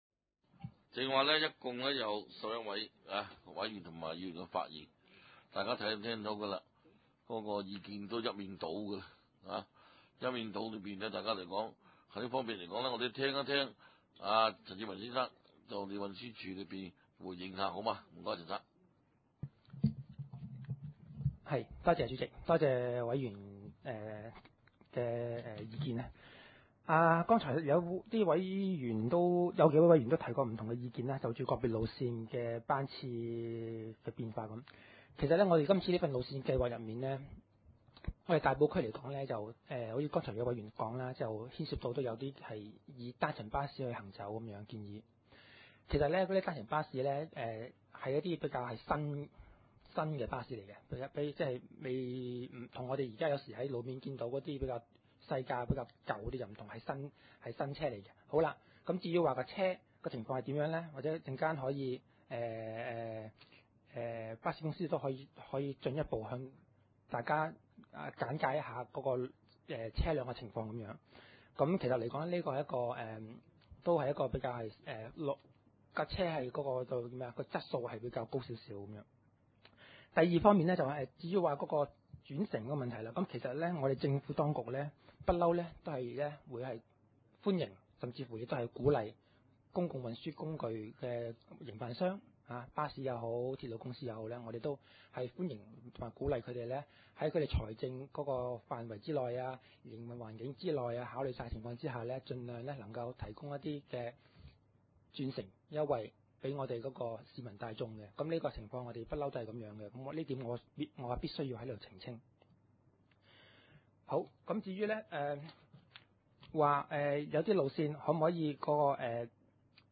大埔區議會 交通及運輸委員會 2009 年第一次會議 日期：2009年1月16日 (星期五) 時間：下午2時30分 地點：大埔區議會秘書處會議室 議 程 討論時間 I. 通過交通及運輸委員會 2008 年第六次會議 (14.11.2008) 記錄 00:40 ( 大埔區議會文件 TT 1/2009 號 ) II. 續議交通及運輸委員會2008年第六次會議(14.11.2008)事項 31:38 (大埔區議會文件TT 2/2009號) III. 2009至2010年度大埔區巴士路線發展計劃 19:23 (大埔區議會文件TT 3/2009號) 19:48 IV.